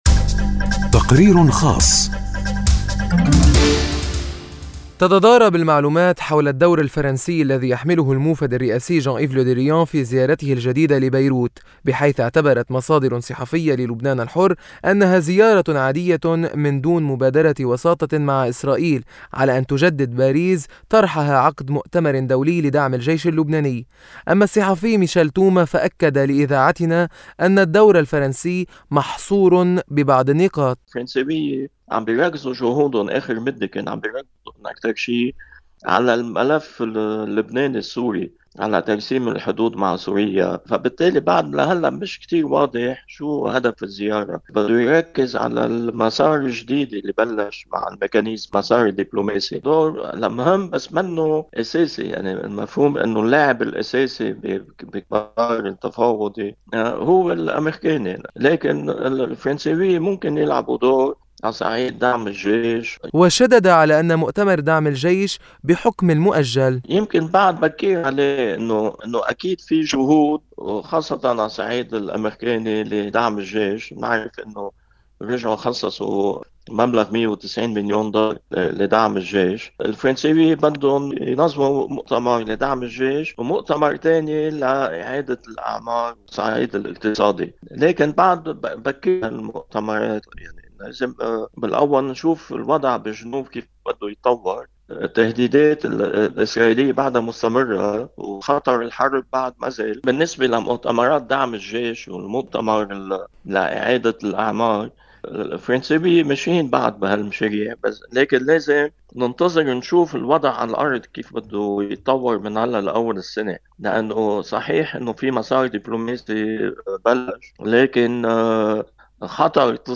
فهلْ تخْتلف المبادرة الفرنسيّة هذه المرّة عنْ سابقاتها، وخصوصًا تلْك الّتي حدثتْ في أيْلول والّتي راوحتْ في إطار التّواصل لا أكْثر؟ التقرير